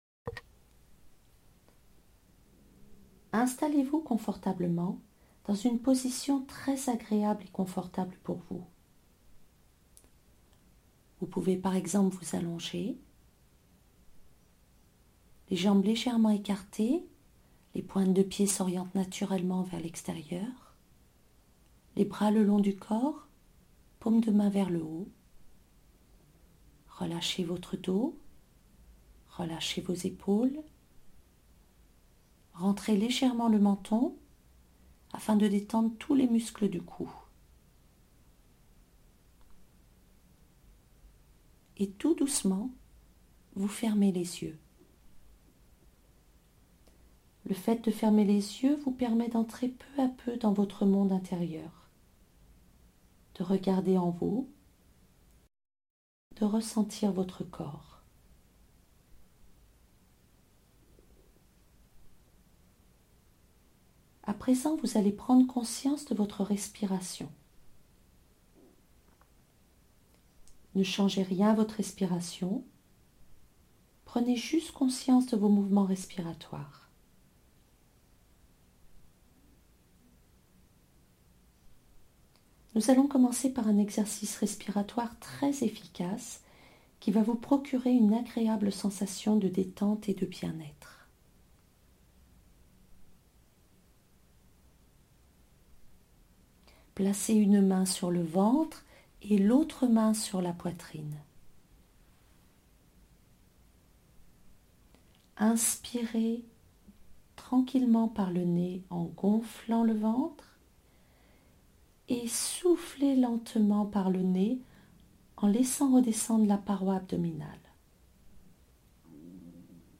mp3-RELAXATION.mp3